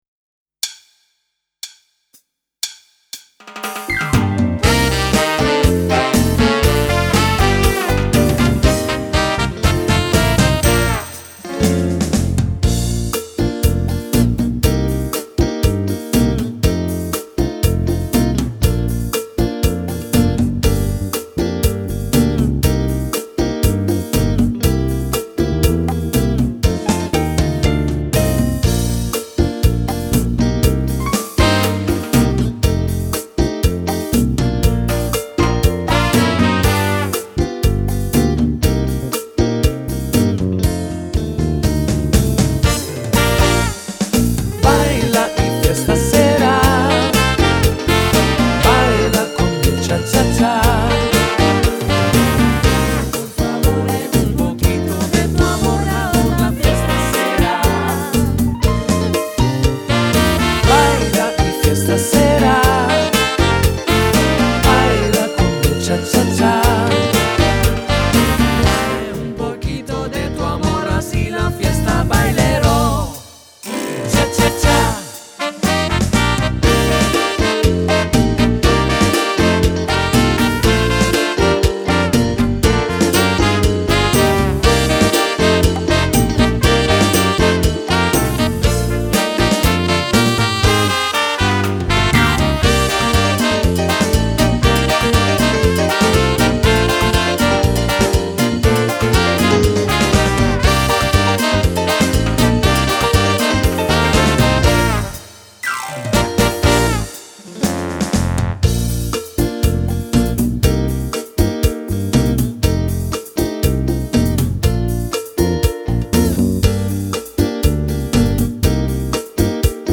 Cha cha cha
Donna